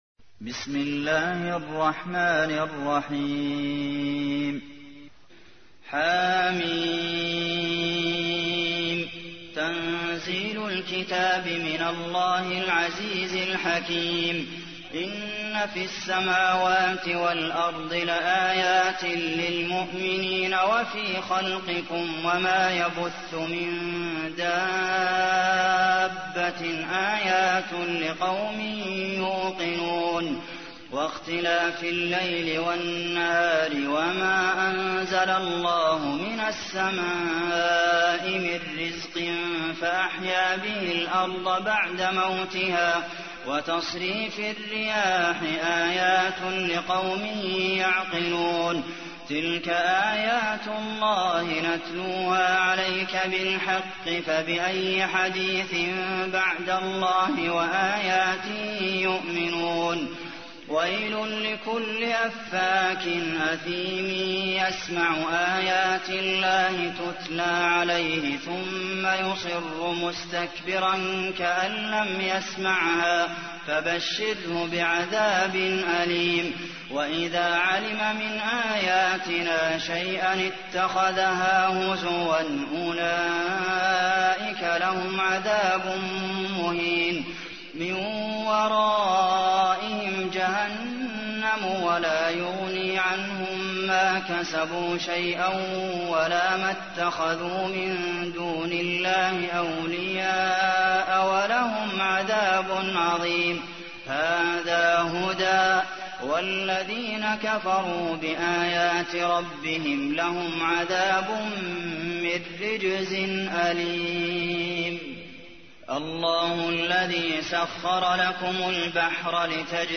تحميل : 45. سورة الجاثية / القارئ عبد المحسن قاسم / القرآن الكريم / موقع يا حسين